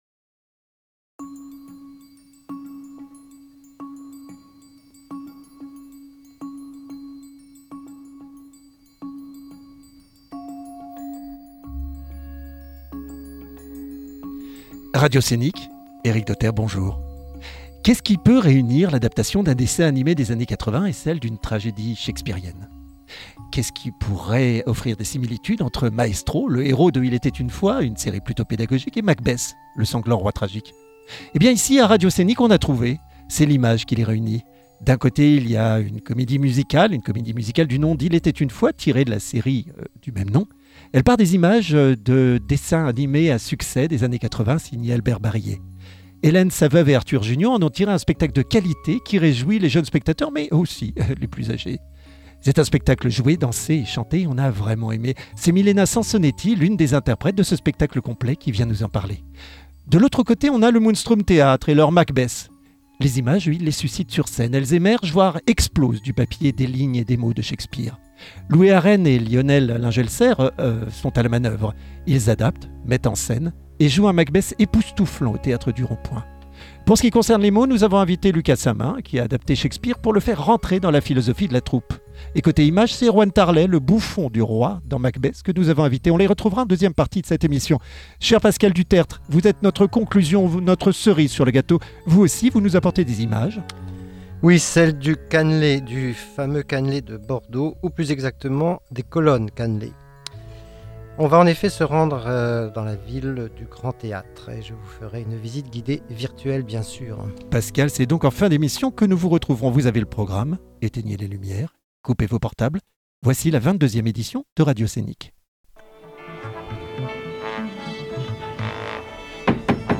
Deux spectacles bien différents mais de grande qualité réunis devant les micros de RADIOSCENIC.